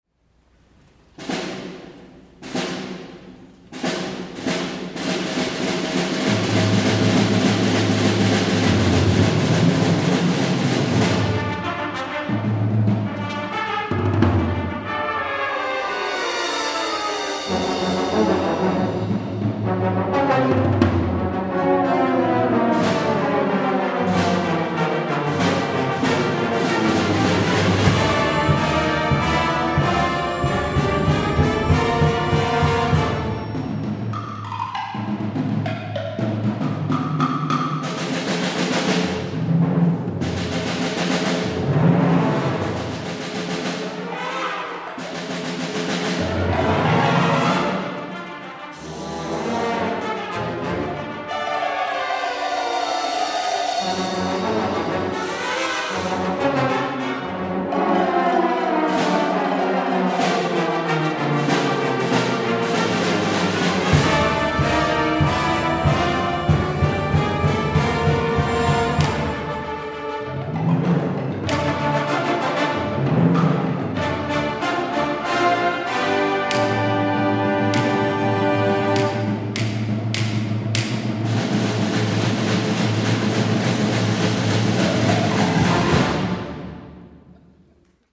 for Band (1989)